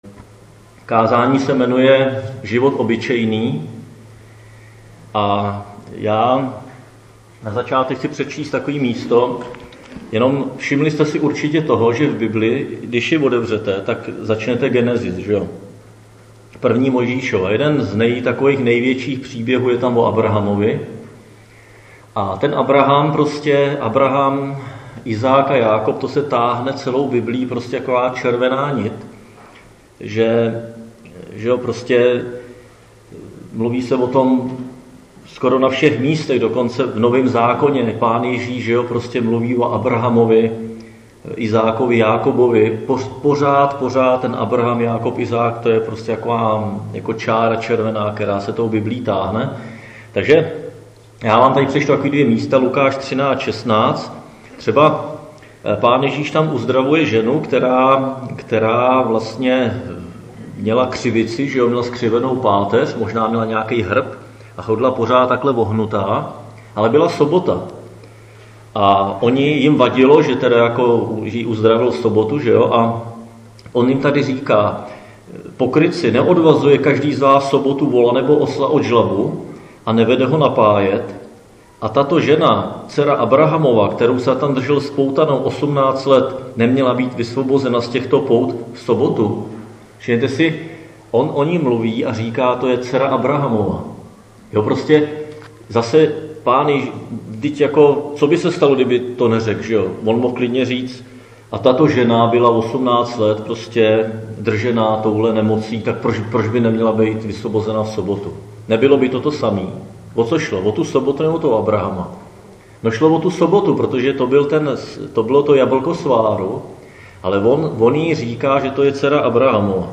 Křesťanské společenství Jičín - Kázání 23.7.2017